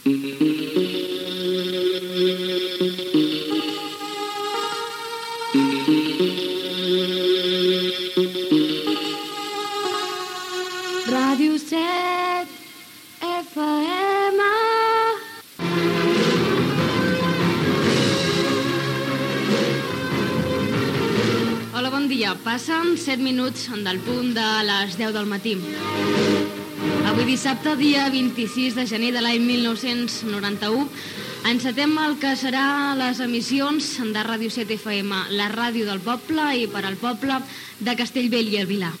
Indicatiu de l'emissora, hora, data, presentació en el primer dia d'emissió